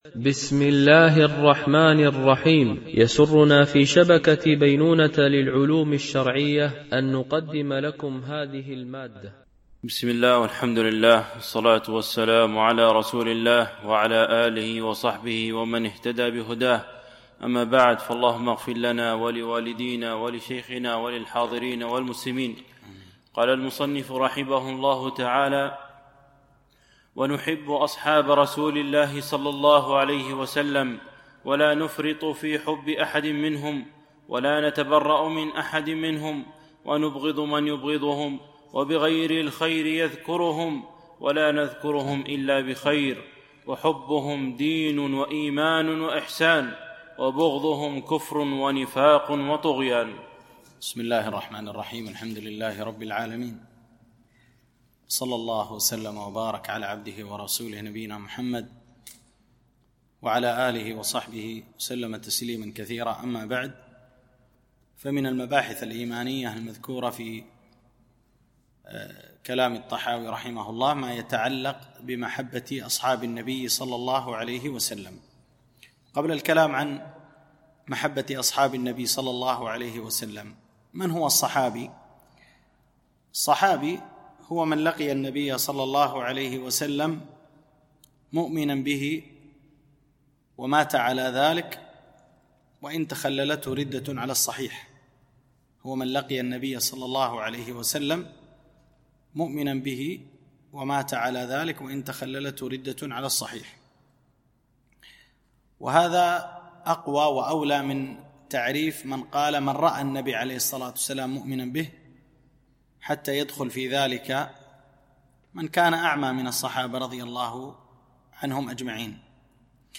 مباحث إيمانية - الدرس 14